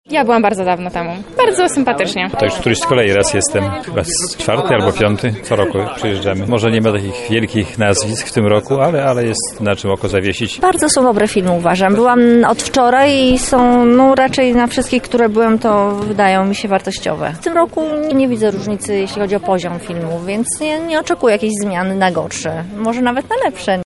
Zapytaliśmy widzów, która to ich wizyta na festiwalu i co sądzą o tegorocznej edycji.